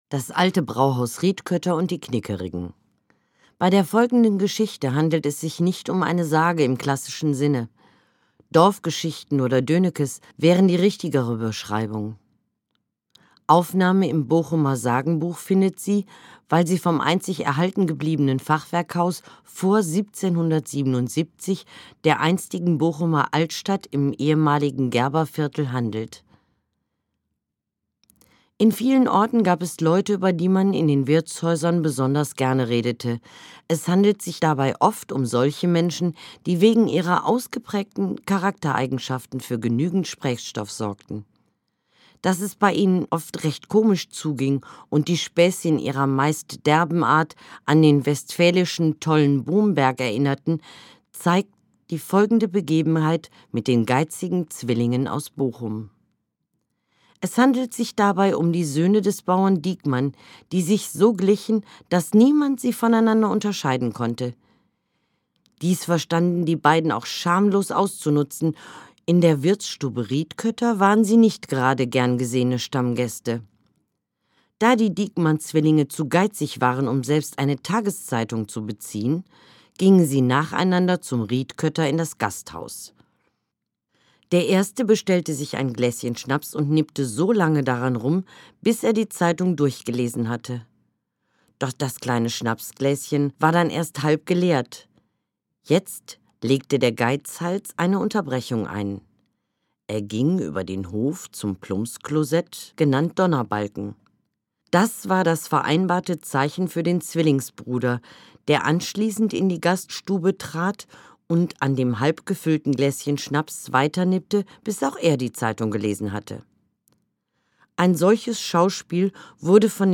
Gelesen von